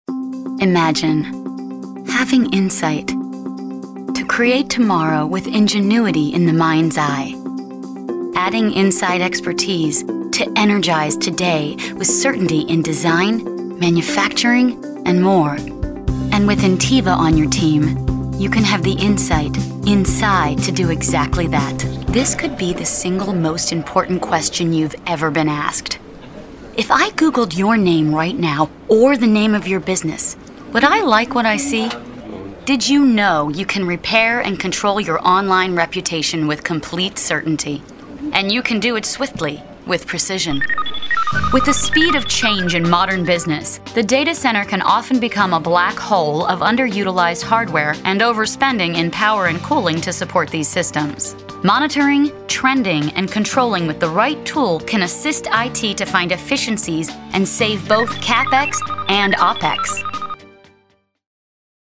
Female Voice Over, Dan Wachs Talent Agency.
Believable, Caring, Conversational.
Corporate